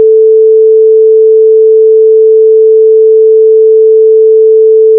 note_440Hz.wav